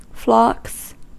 Ääntäminen
Ääntäminen US Haettu sana löytyi näillä lähdekielillä: englanti Käännöksiä ei löytynyt valitulle kohdekielelle. Flocks on sanan flock monikko.